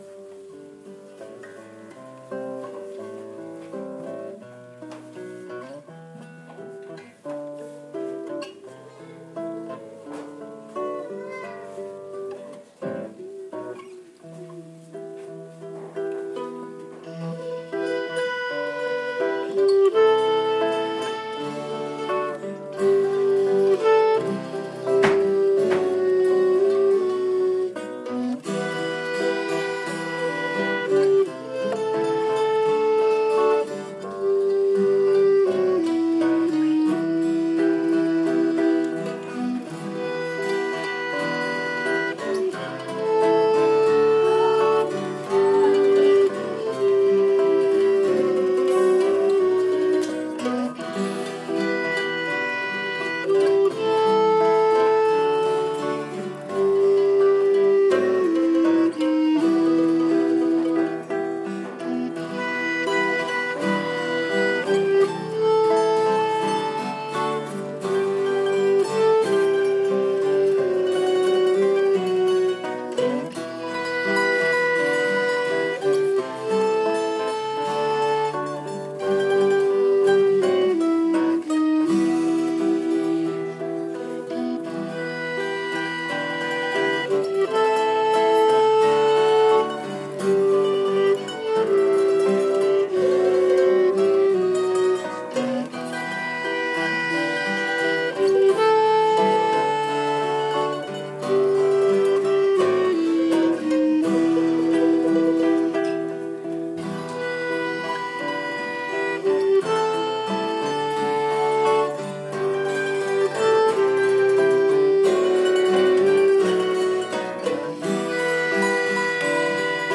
Sunday Instrumental